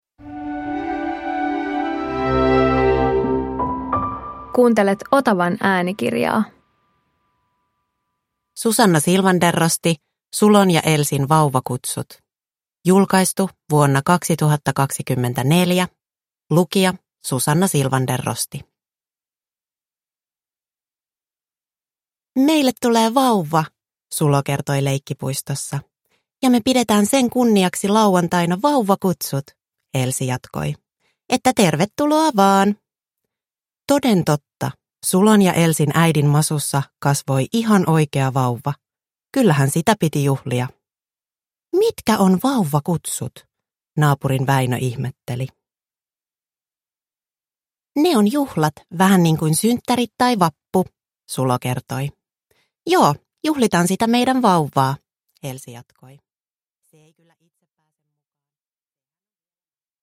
Sulon ja Elsin vauvakutsut – Ljudbok